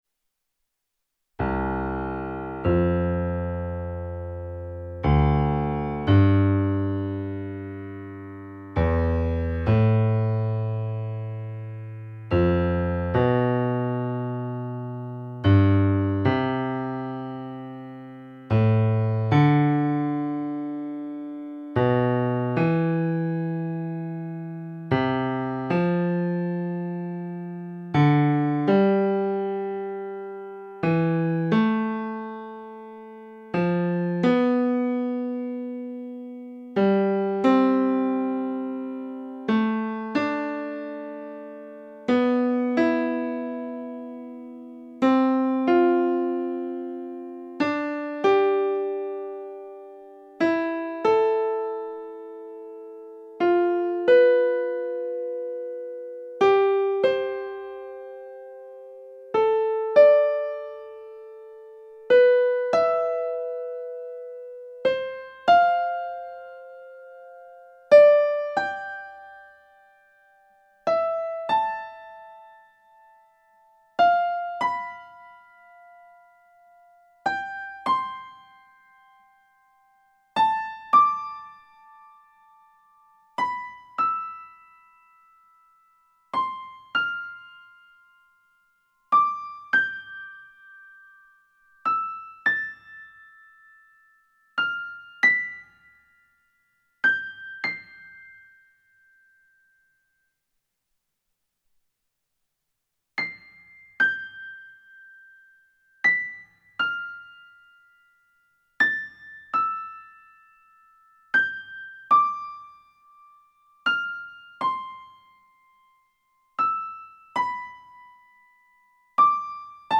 Tonleitern zum Mitsingen
aufsteigende und absteigende Quarten
tonleitern-quarten.mp3